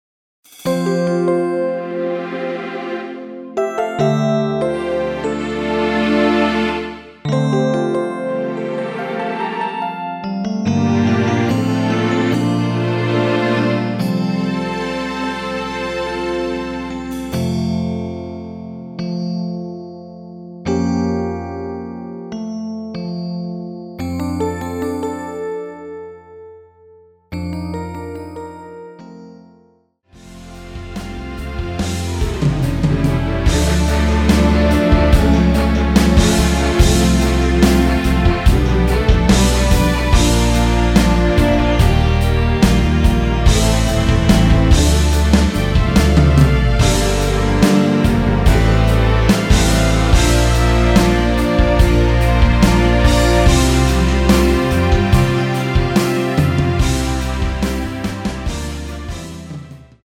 ◈ 곡명 옆 (-1)은 반음 내림, (+1)은 반음 올림 입니다.
음정은 반음정씩 변하게 되며 노래방도 마찬가지로 반음정씩 변하게 됩니다.
앞부분30초, 뒷부분30초씩 편집해서 올려 드리고 있습니다.
중간에 음이 끈어지고 다시 나오는 이유는